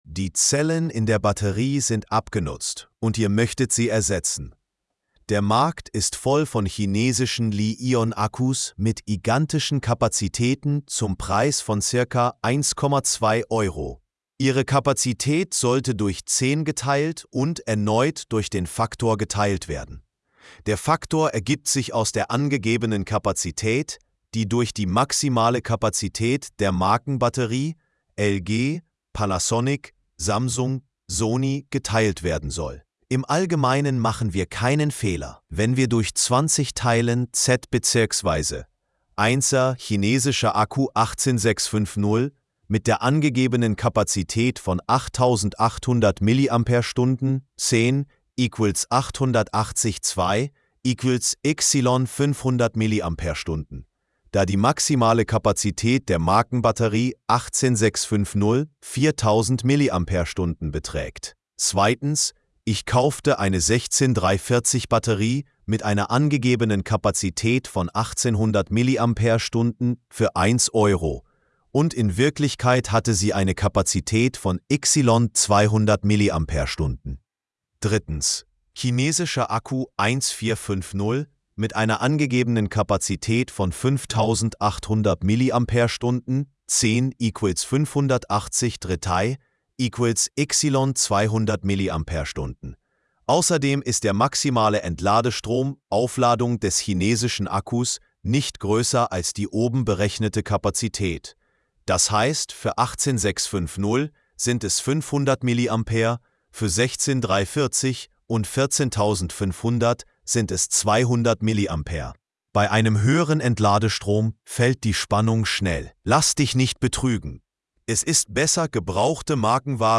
📢 Anhören (AI):